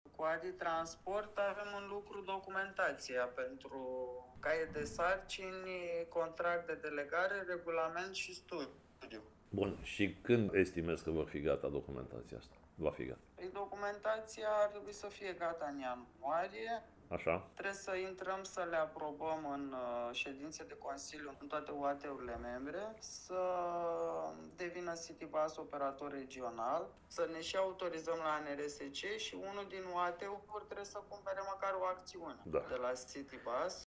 După ce i-a transmis că este în mijlocul întâlnirii cu echipa noastră, primarul i-a cerut mai multe detalii despre stadiul proiectului Asociației de Dezvoltare Intercomunitară ADI Transport: